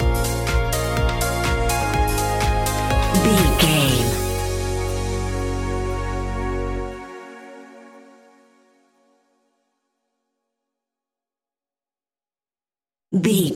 Aeolian/Minor
B♭
groovy
hypnotic
uplifting
drum machine
synthesiser
house
electro house
instrumentals
synth bass